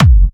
VEC3 Clubby Kicks
VEC3 Bassdrums Clubby 005.wav